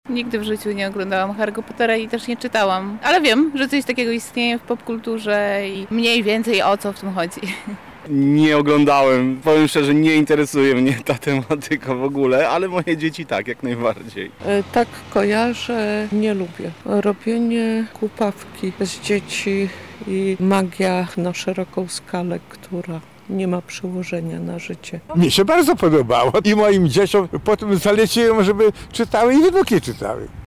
Zapytaliśmy lubelskich przechodniów, czy kojarzą tytułową postać.
Mieszkańcy